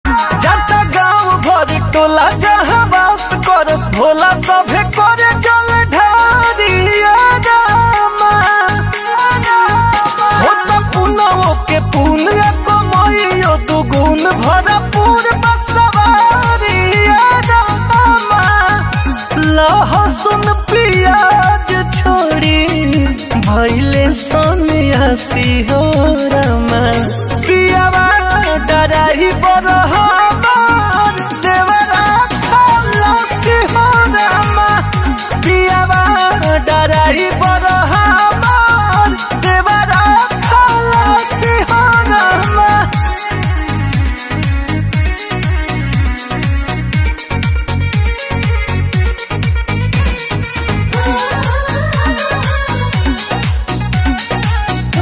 Bhojpuri Ringtones